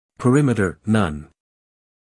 英音/ pəˈrɪmɪtə(r) / 美音/ pəˈrɪmɪtər /